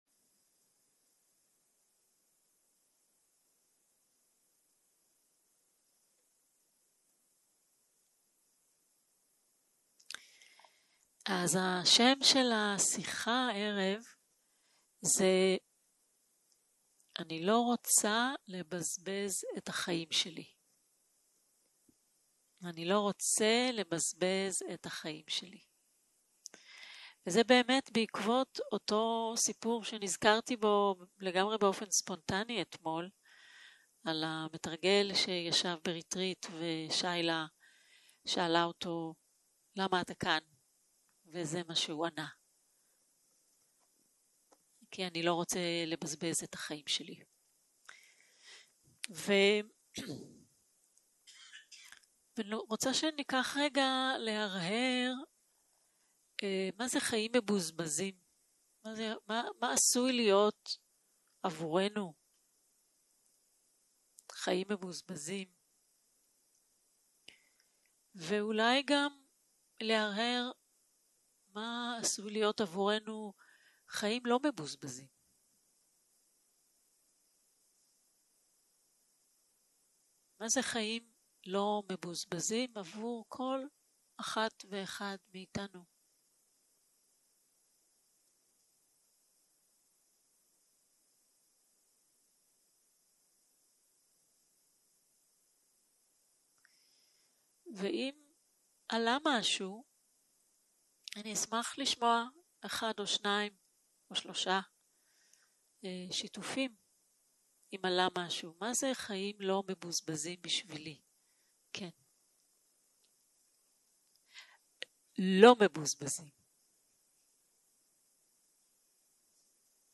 יום 2 - ערב - שיחת דהרמה - אני לא רוצה לבזבז את החיים שלי - הקלטה 4 0:00 0:00 סוג ההקלטה: Dharma type: Dharma Talks שפת ההקלטה: Dharma talk language: Hebrew